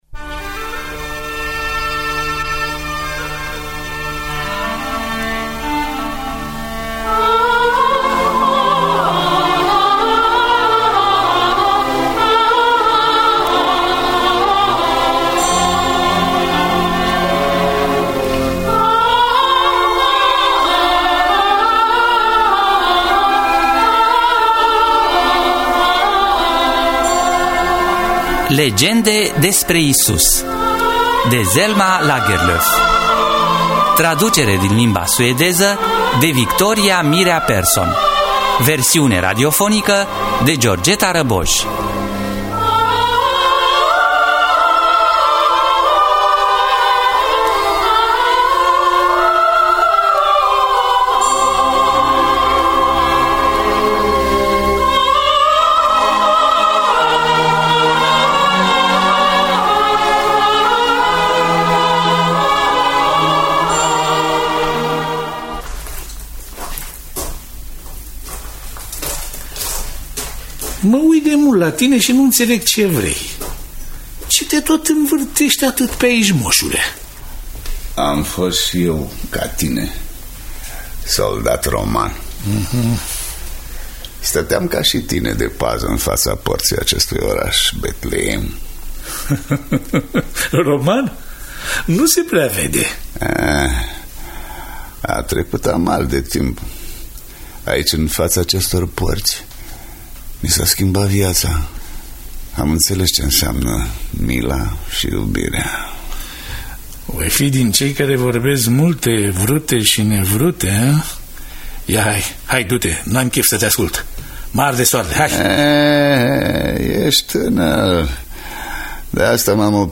„Legende despre Iisus” de Selma Lagerloff – Teatru Radiofonic Online